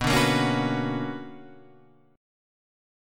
BmM9 chord {7 5 8 6 7 7} chord